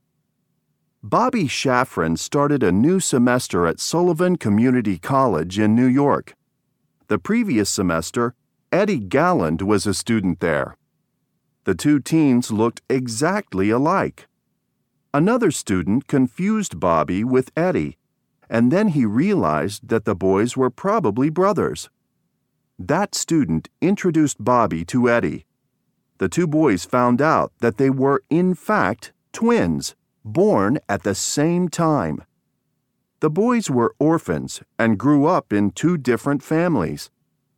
قطعه قرائيه جاهزه للصف الثالث متوسط ف2 الوحده السابعه mp3